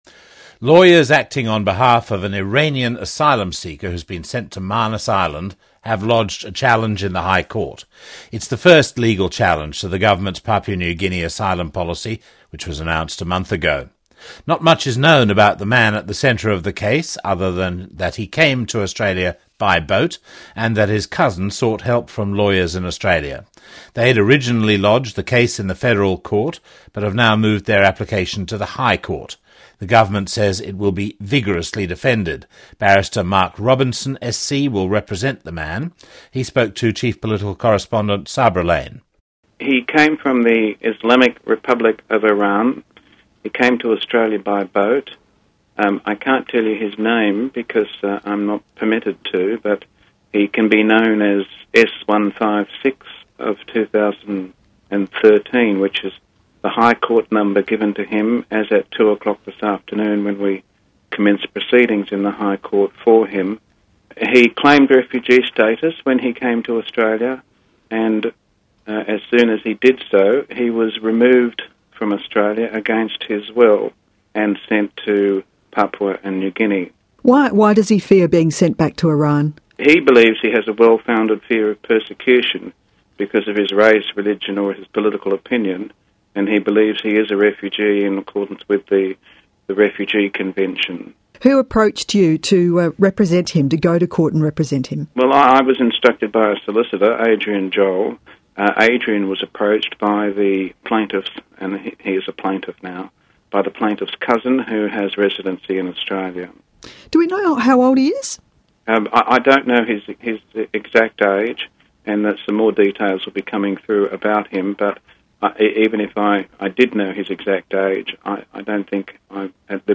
ABC – 20 August 2013